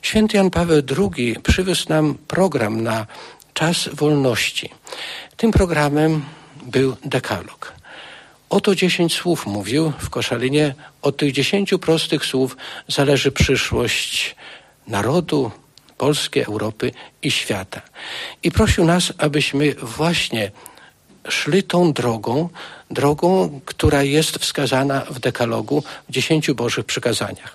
Uczestnicy dzisiejszej (13.09.) konferencji dotyczącej festiwalu 'Iskra Nadziei. Podlaskie w hołdzie Janowi Pawłowi II’ wspominali papieża Polaka.
O pielgrzymce Ojca Świętego do Polski mówił Biskup Pomocniczy Diecezji Łomżyńskiej, Tadeusz Bronakowski: